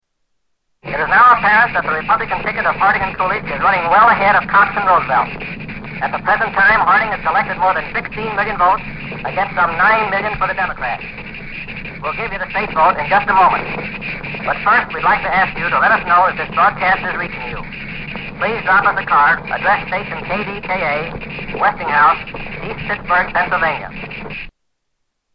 1920 Presidential election results from Station KDKA
Category: Radio   Right: Personal